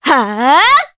Artemia-Vox_Attack4_jp.wav